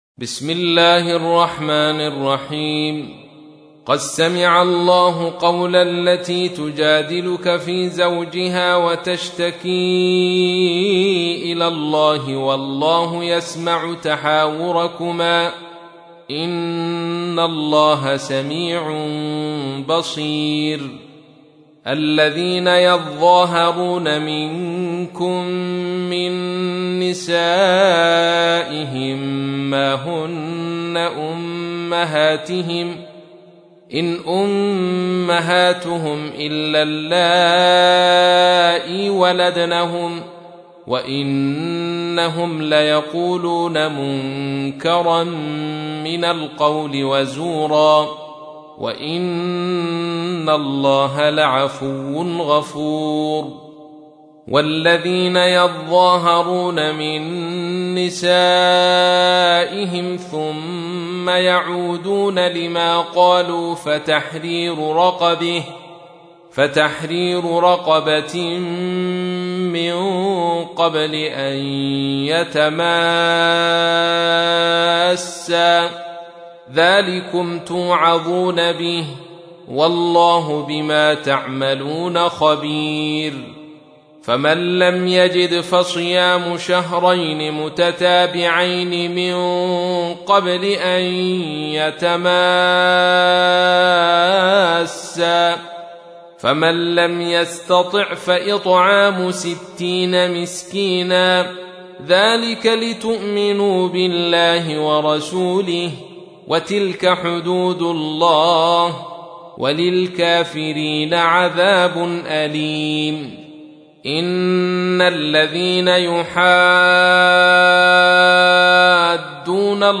تحميل : 58. سورة المجادلة / القارئ عبد الرشيد صوفي / القرآن الكريم / موقع يا حسين